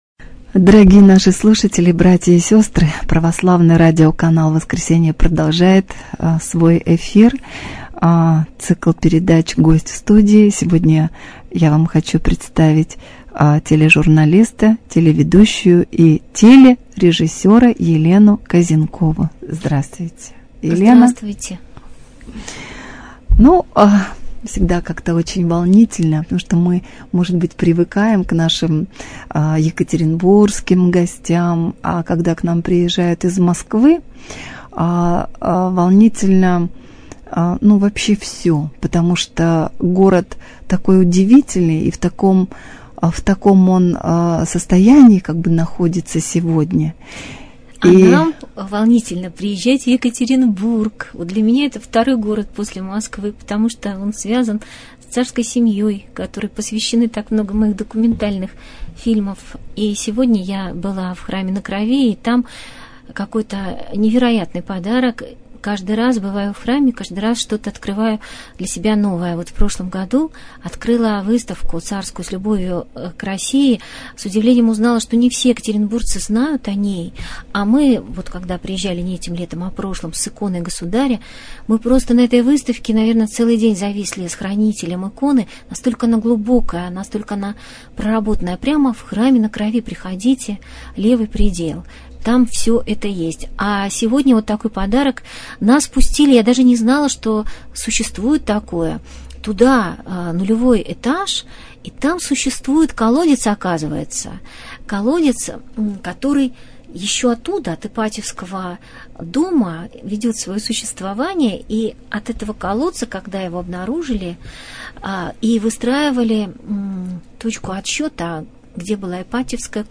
Гость в студии | Православное радио «Воскресение»